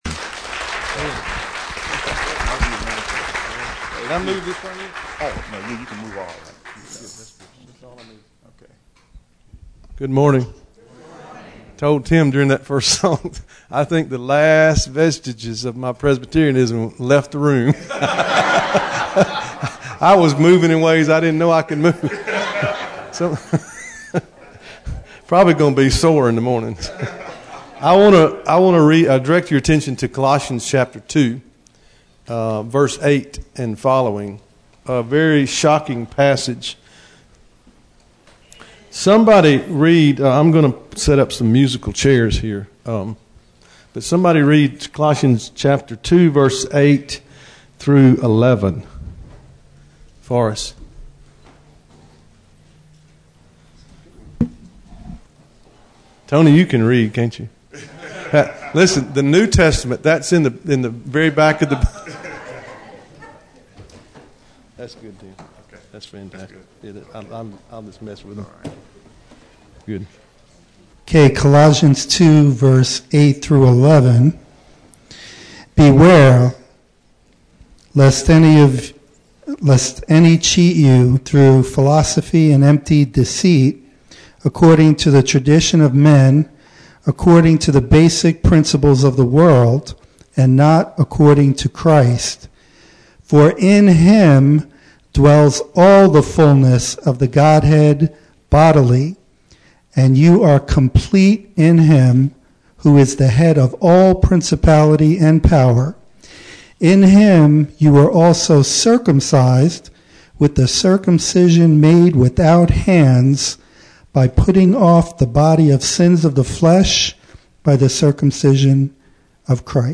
We think he met his goal in preaching to us!